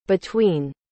¿Cómo se pronuncia correctamente between?
Se pronuncia /bɪˈtwiːn/, algo así como «bituín».
• La sílaba fuerte es la segunda: tween.